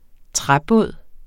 Udtale [ ˈtʁaˌbɔˀð ]